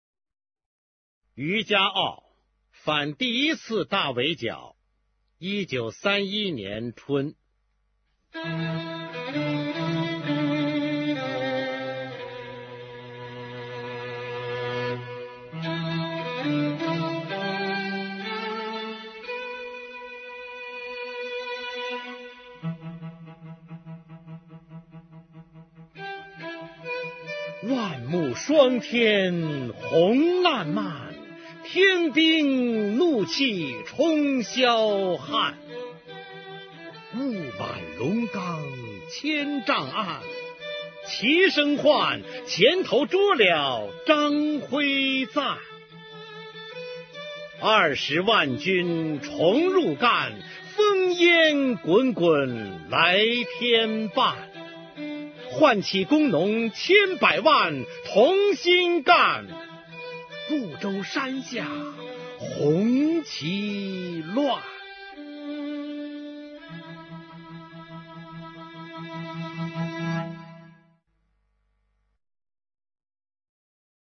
[毛泽东诗词朗诵]毛泽东-渔家傲·反第一次大围剿（男） 配乐朗诵